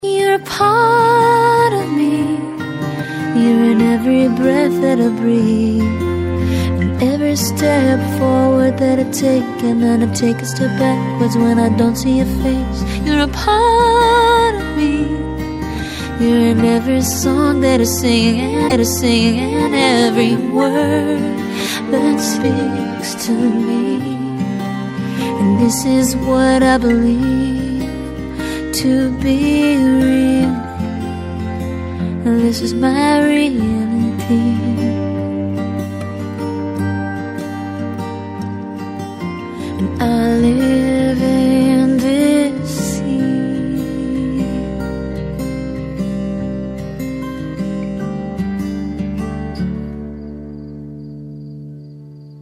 из Клубные